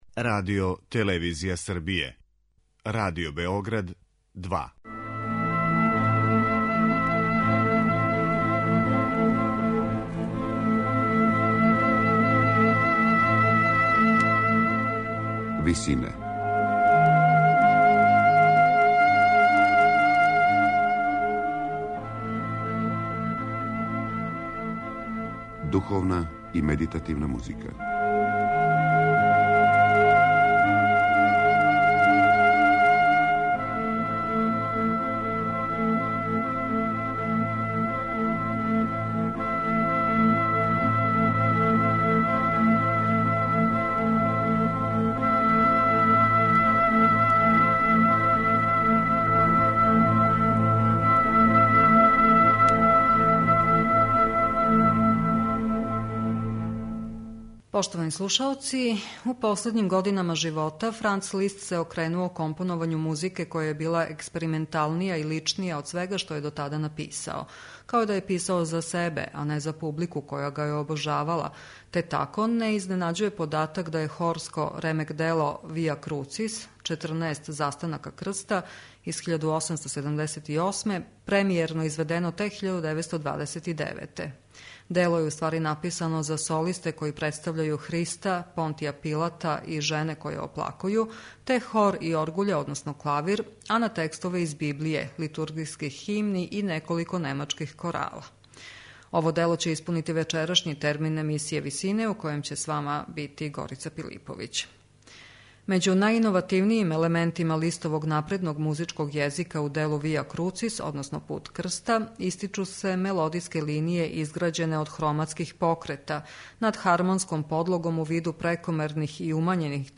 Листово хорско ремек-дело
У емисији Висине можете слушати хорско ремек-дело Via crucis, Франца Листа, настало у последњим годинама композиторовог живота, када се окренуо религији.